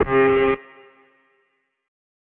0 Cowbell Phonk Wet Falling